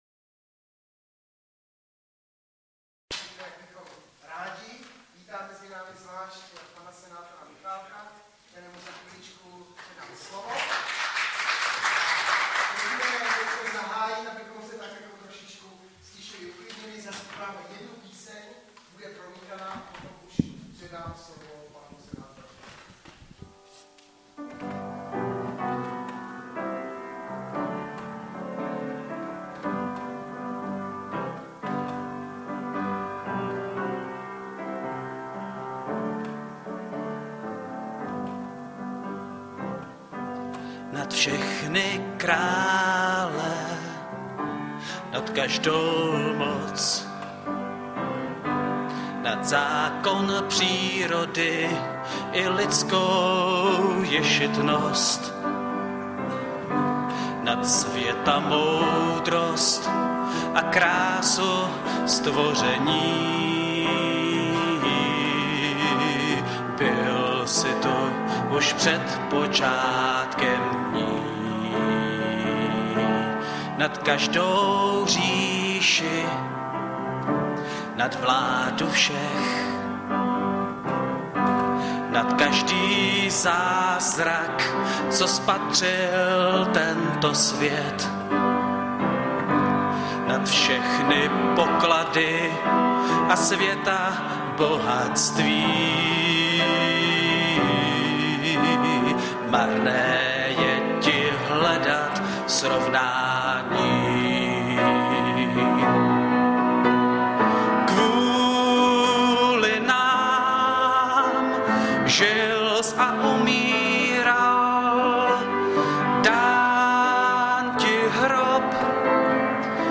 09.11.2017 - Přednáška senátora a křesťana L. Michálka